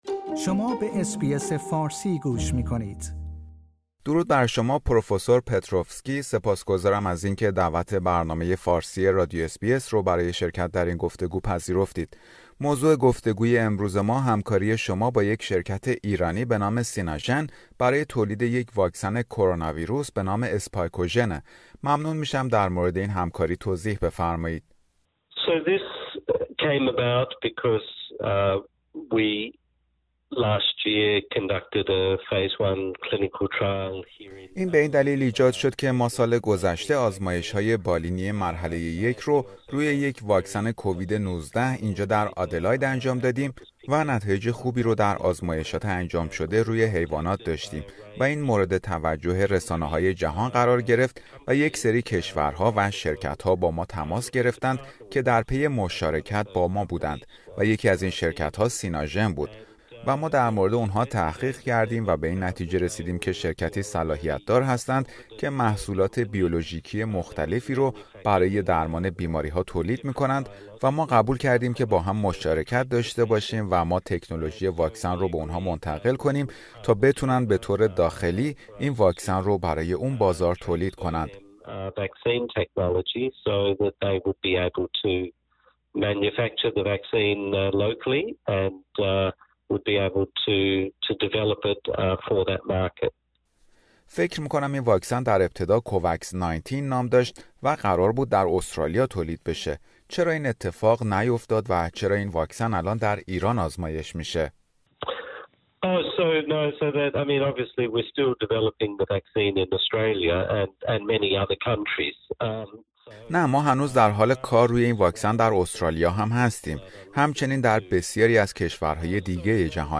برنامه فارسی رادیو اس بی اس در همین خصوص گفتگویی داشته